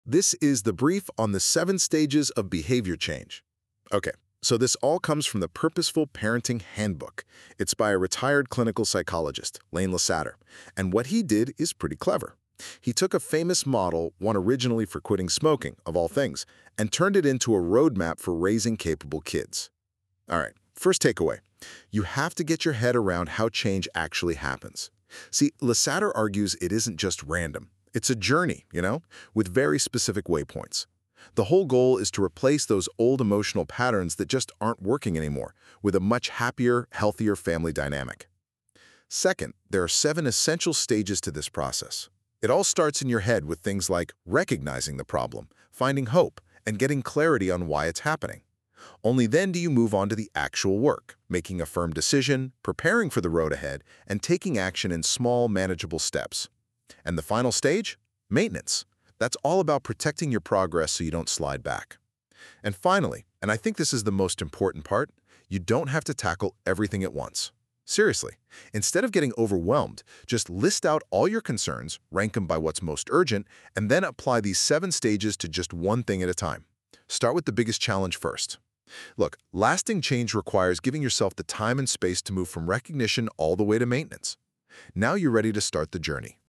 Each post contains my written material, an AI generated graphic, a 15-17 minute audio summary, and a 5-7 minute video summarizing the material.